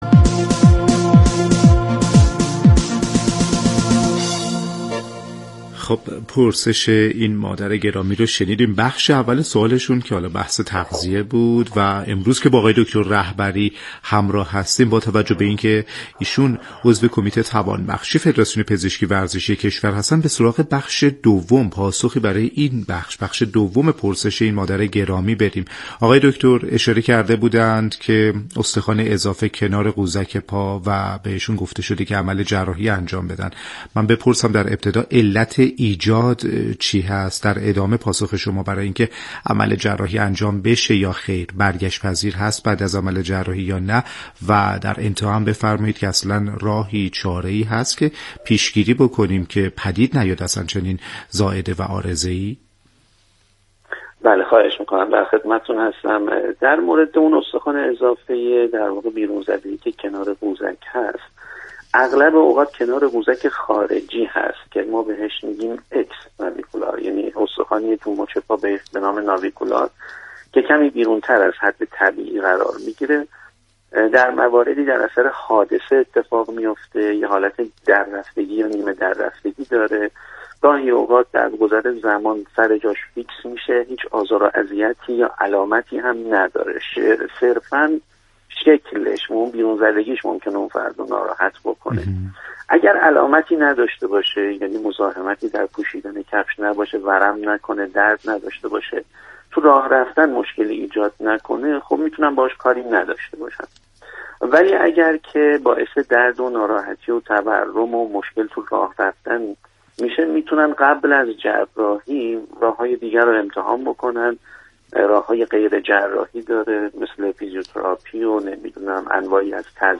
در گفت وگو با برنامه نسخه ورزشی رادیو ورزش